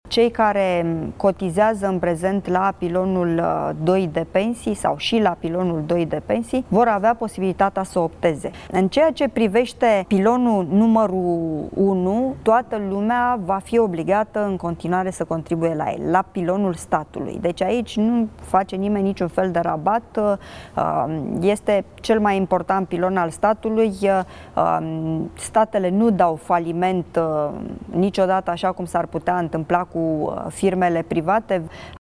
Al doilea argument cu care ministrul Muncii pledează public pentru trecerea exclusiv la fondul de pensii administrat de stat a fost detaliat într-un interviu recent la Antena 3:  „Cei care cotizează în prezent la Pilonul II de pensii – sau și la Pilonul II de pensii – vor avea posibilitatea să opteze. În ceea ce privește Pilonul I, toată lumea va fi obligată, în continuare, să contribuie la el. Aici nu face nimeni niciun fel de rabat. Este cel mai important pilon al statului. Statele nu dau faliment, așa cum s-ar putea întâmpla cu firmele private.”